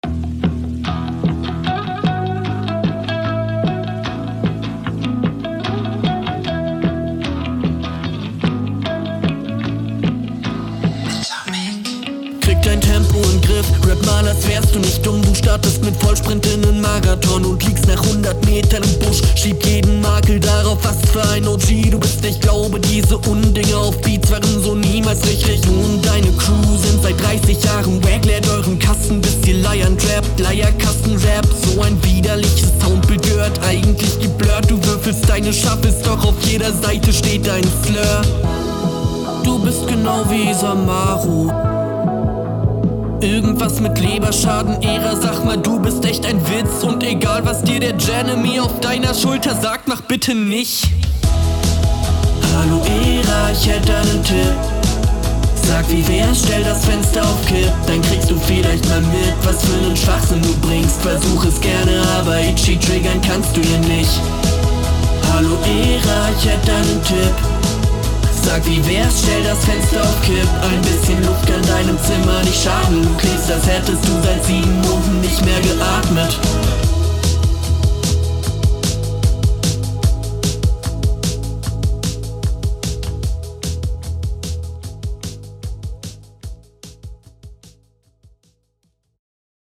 Angenehmer Hörgenuss bei der Runde, kreativ bist du auf jedenfall und echt melodisch!